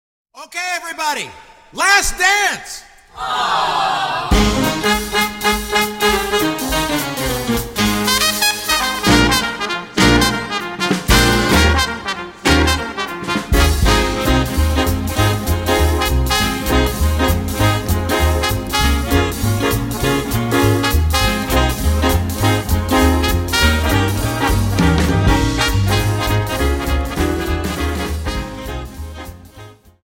Dance: Quickstep
(Quickstep 50)